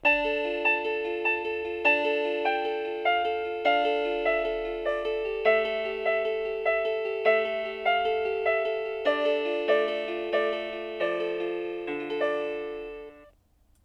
• 36 vyzváněcí tónů k výběru, ukázky zvonění: